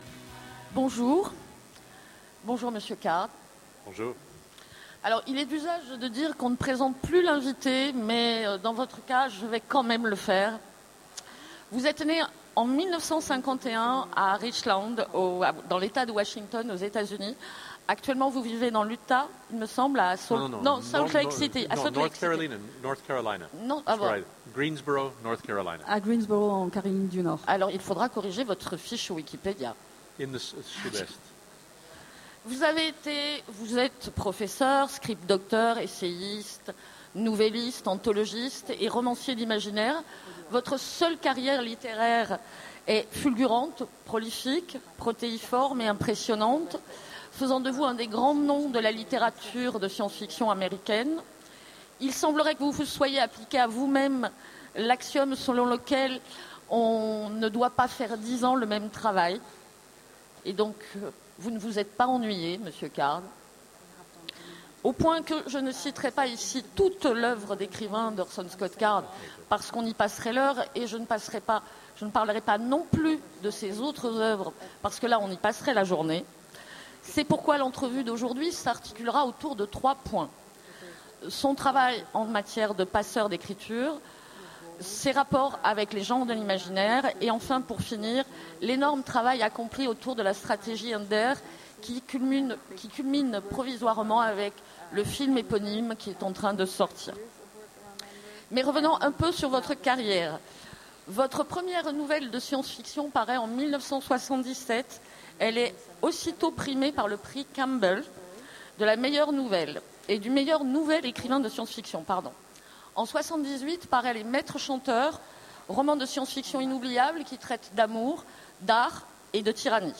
Utopiales 13 : Conférence Rencontre avec Orson Scott Card
- le 31/10/2017 Partager Commenter Utopiales 13 : Conférence Rencontre avec Orson Scott Card Télécharger le MP3 à lire aussi Orson Scott Card Genres / Mots-clés Rencontre avec un auteur Conférence Partager cet article